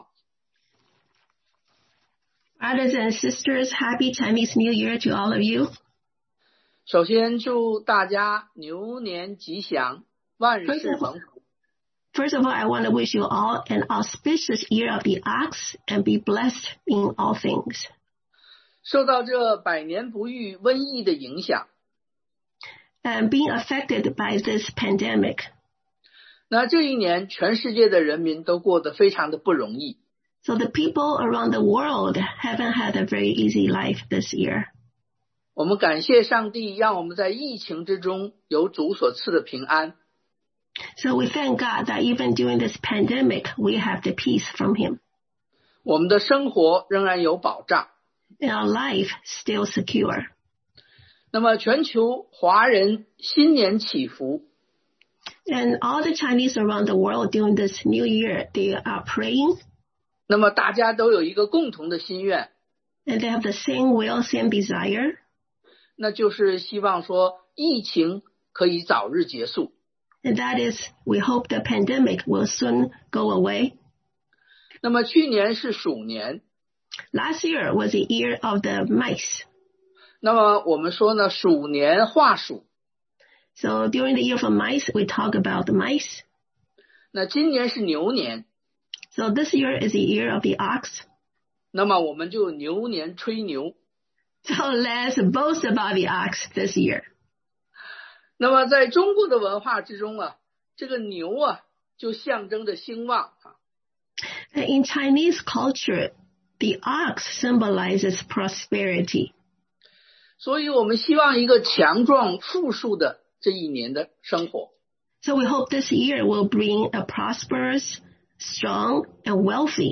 2021 Chinese New Year Special Worship2021牛年春節特別崇拜
Service Type: Sunday AM